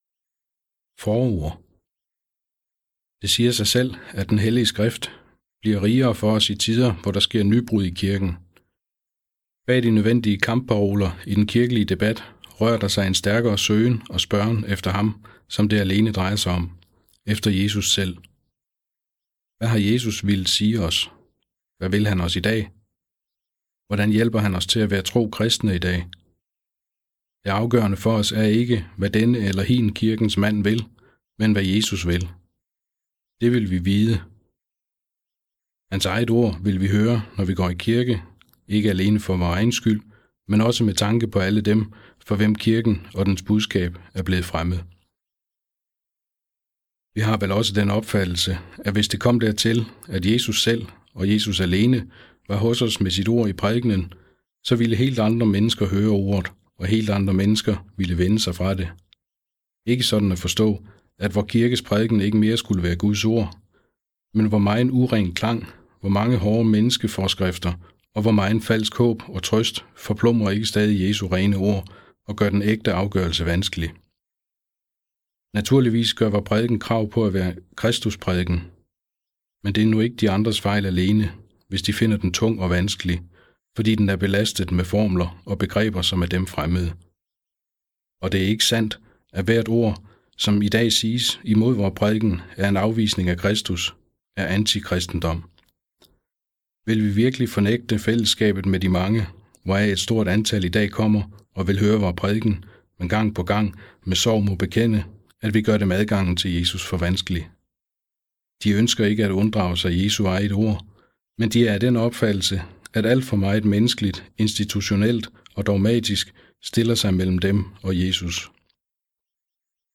Hør et uddrag af Efterfølgelse Efterfølgelse Credo Klassiker Format MP3 Forfatter Dietrich Bonhoeffer Bog Lydbog 149,95 kr.